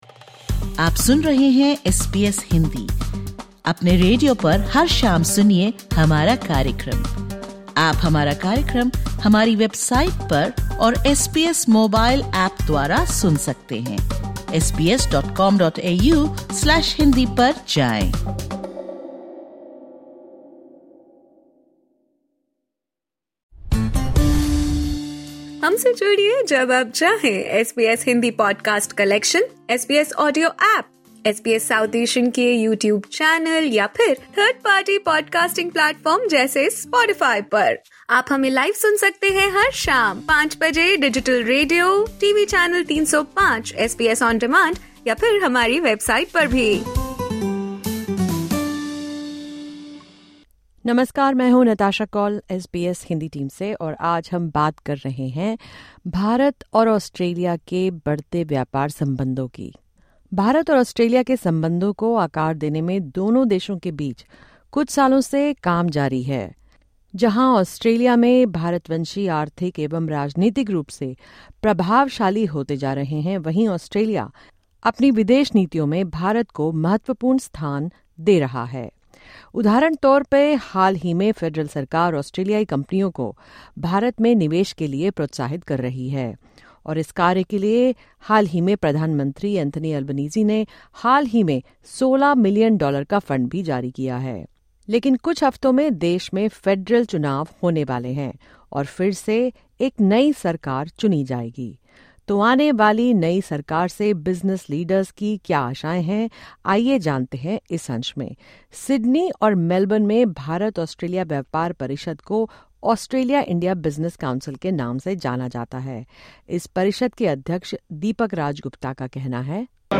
The Australia India Business Council (AIBC) recently held a panel discussion in Melbourne, titled Passage to India – Shaping Future Partnerships: Opportunities for Australian Businesses with the Make in India Initiative. In this podcast episode, SBS Hindi speaks with participants and business leaders about their expectations from the new government ahead of the upcoming federal elections. The Indian Consul General in Melbourne, Dr Sushil Kumar, also highlights the strength of Australia-India relations, describing them as the strongest they have ever been.